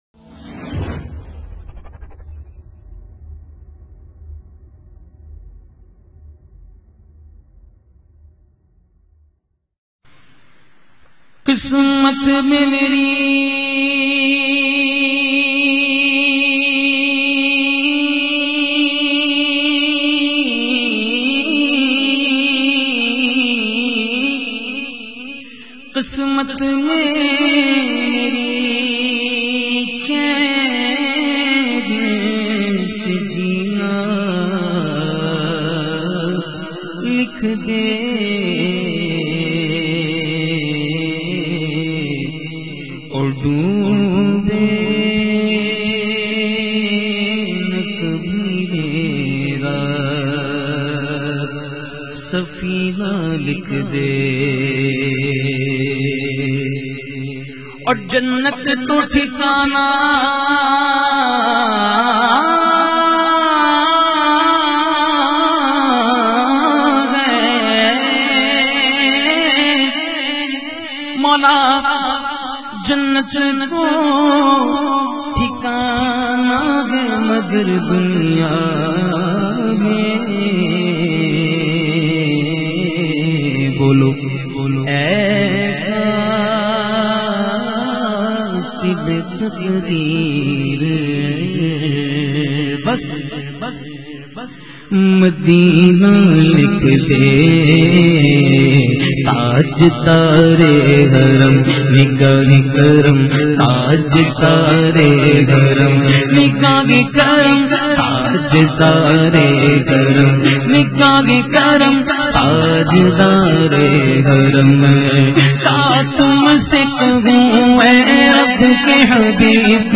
in a Heart-Touching Voice